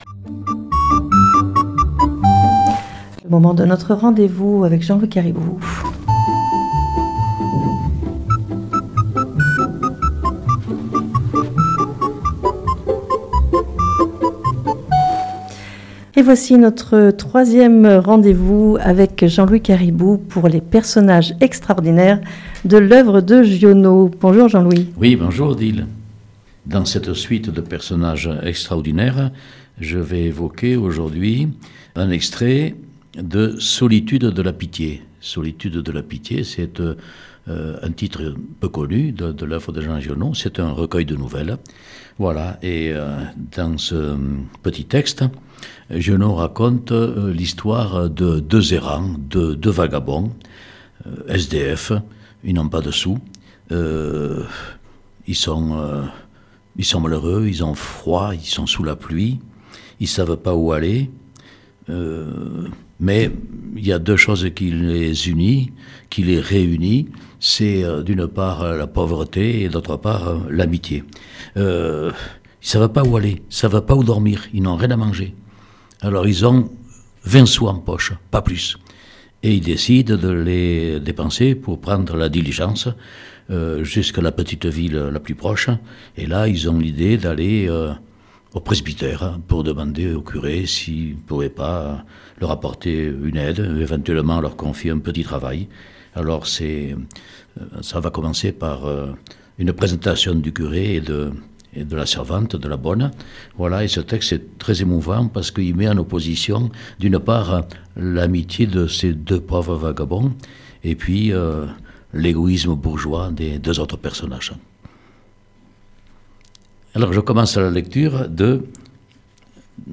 nous lit des textes choisis dans le recueil de nouvelles